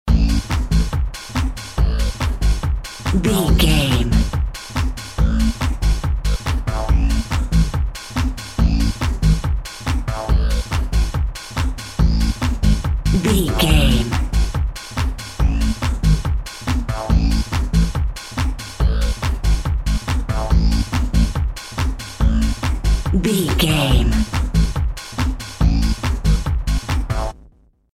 House Music in the 90s.
Fast
energetic
uplifting
futuristic
hypnotic
drum machine
synthesiser
electro house
electronic